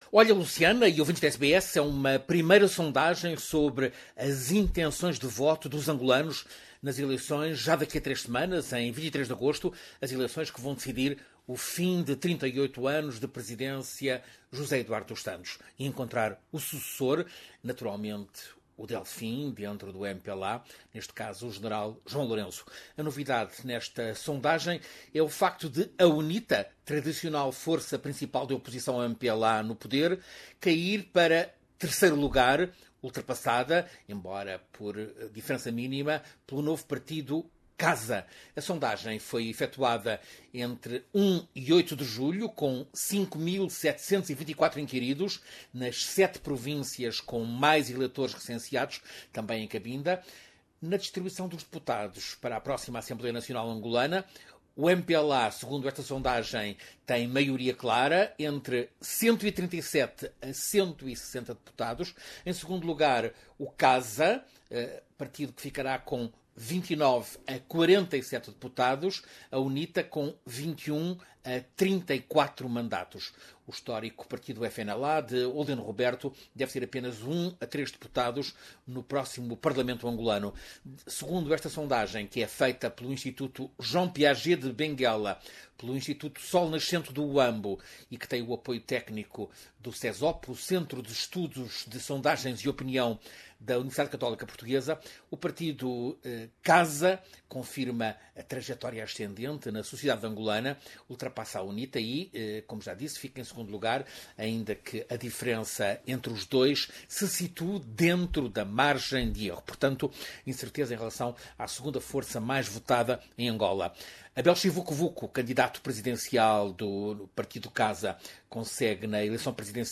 A surpresa neste estudo de opinião que teve assistência técnica da Universidade Católica Portuguesa é a queda da UNITA, apanhada pelo novo partido CASA. Ouça reportagem